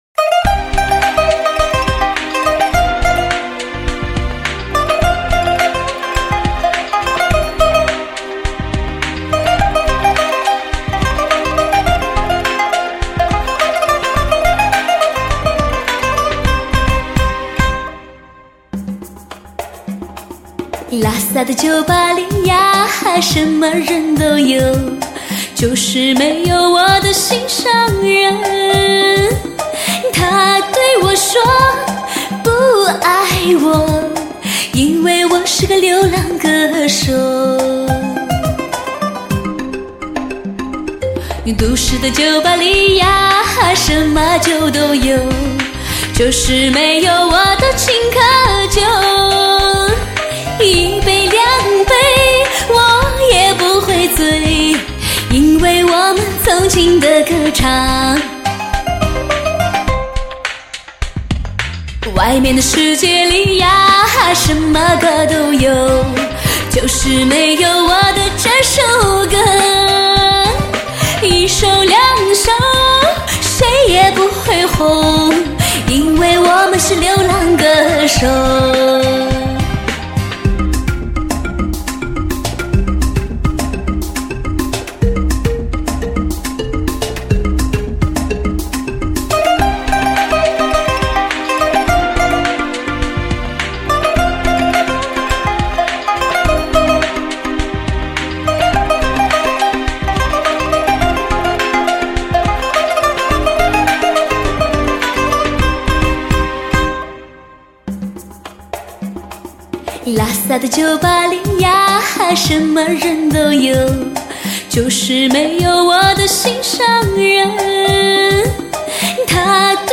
精彩民曲尊贵之声，融传统于现代，予经典于流行。
为低音质MP3